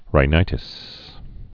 (rī-nītĭs)